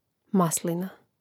mȁslina maslina